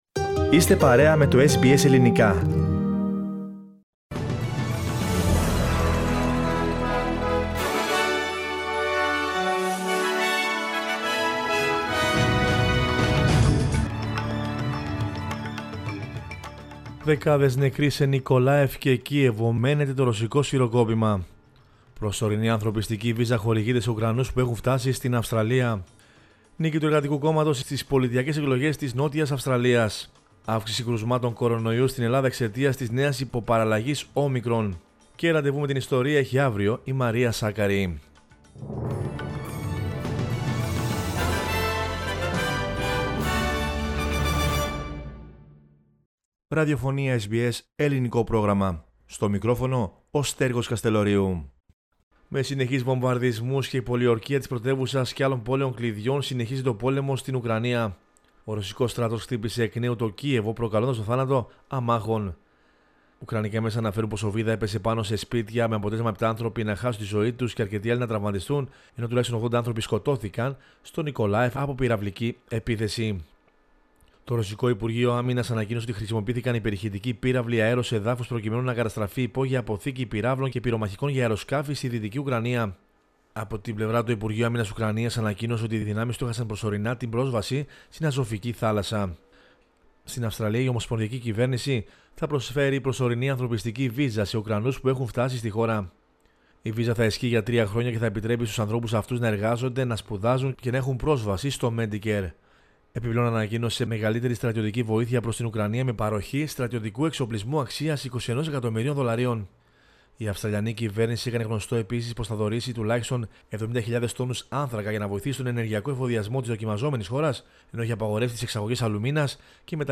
News in Greek from Australia, Greece, Cyprus and the world is the news bulletin of Sunday 20 March 2022.